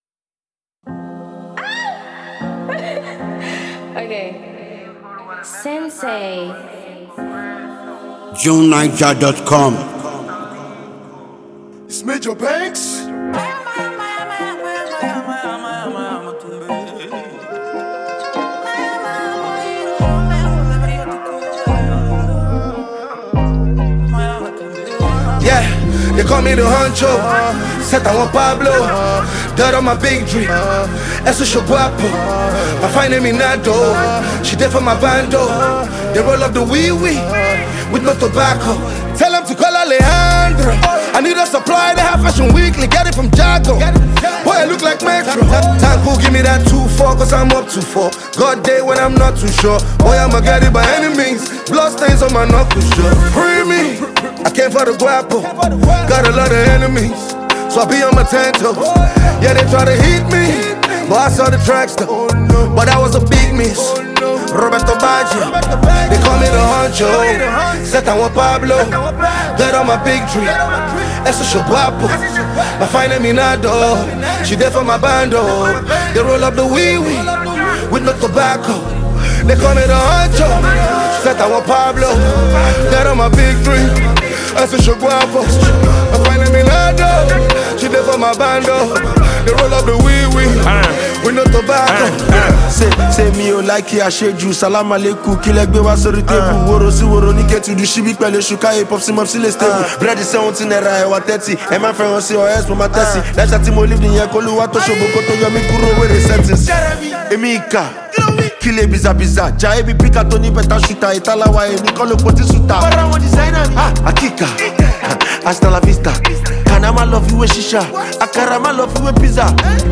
a well-known Nigerian music superstar and gifted songwriter
renowned Nigerian rapper
incredibly gifted Nigerian singer and rapper